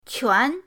quan2.mp3